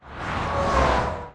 Swoosh 01
标签： 旋风 公路 现场录音
声道立体声